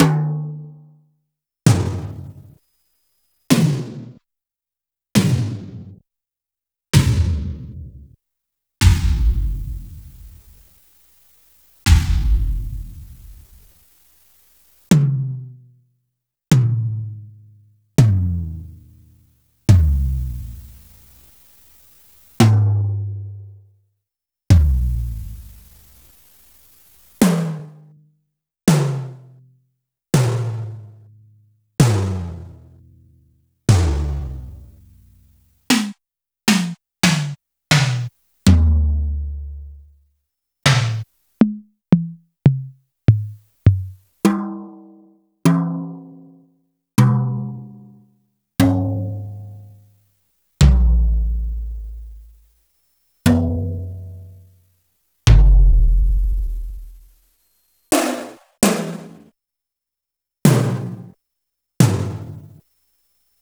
Roland_R-70_Tom.wav